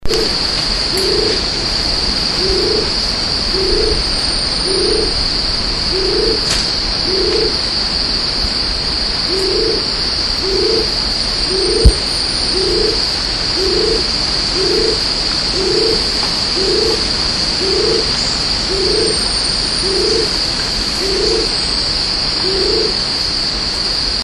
Cream-bellied Fruitdove
Cream-bellied Fruit-Dove.mp3